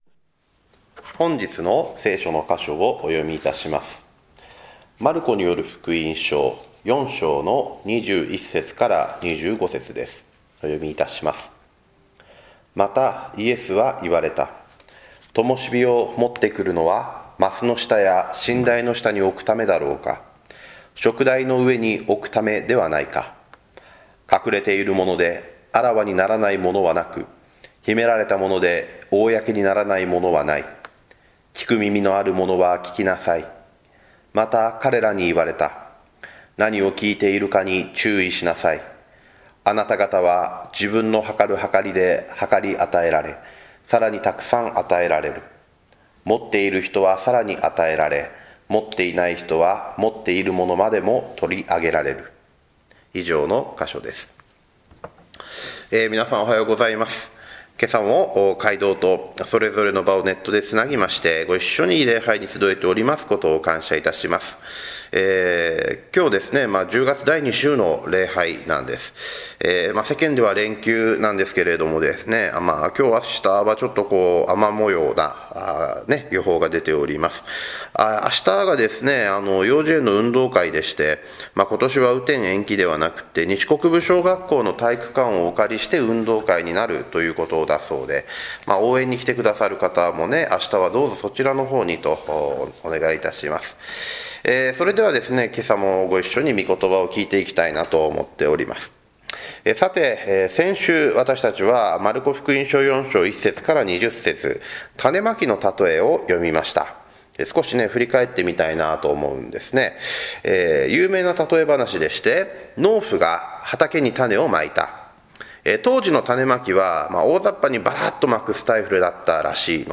宣教 「 小さな一石、大きな確信